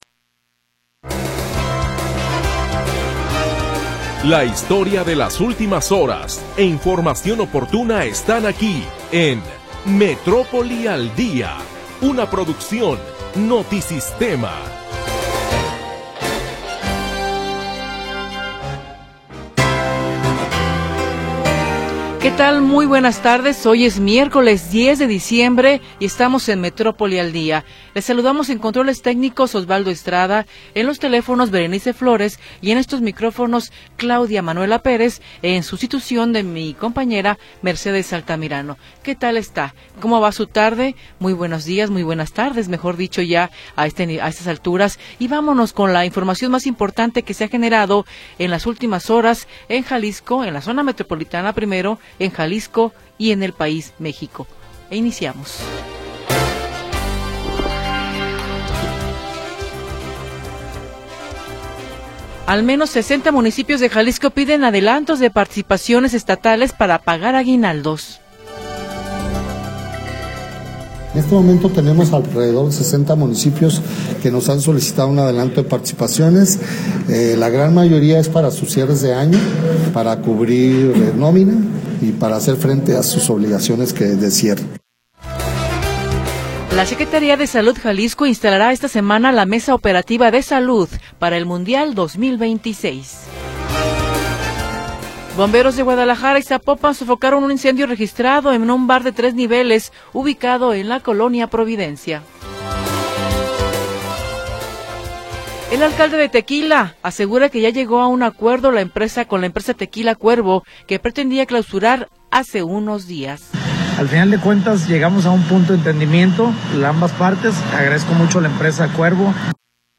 Primera hora del programa transmitido el 10 de Diciembre de 2025.